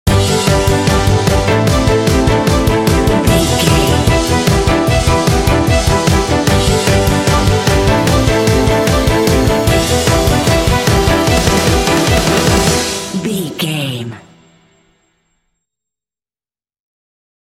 Uplifting
Aeolian/Minor
driving
confident
bright
hopeful
strings
bass guitar
electric guitar
piano
drums
indie
alternative rock